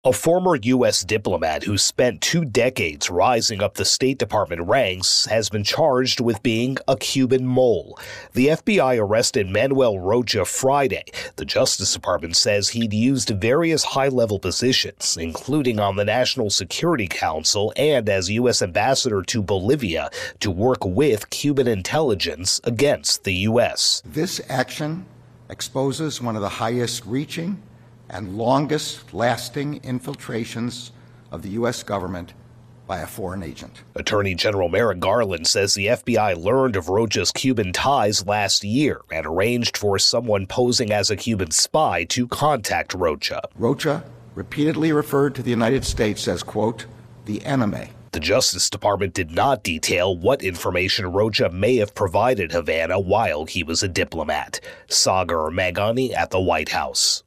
reports on Former Ambassador Arrested.